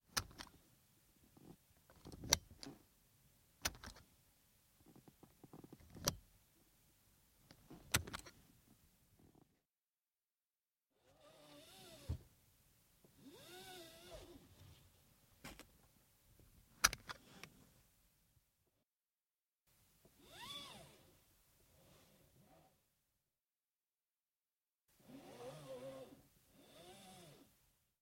Звуки ремня безопасности